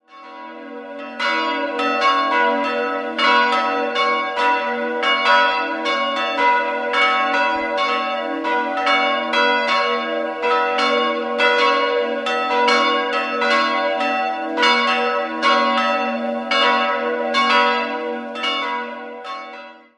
Die Figurenausstattung im Inneren entstammt überwiegend der Spätgotik und der Barockzeit. 3-stimmiges Gloria-Geläute: h'-cis''-e'' Die Glocken 1 und 3 wurden 1950 von Karl Czudnochowsky in Erding gegossen, die mittlere stammt von Karl Hamm (Regensburg) aus dem Jahr 1948.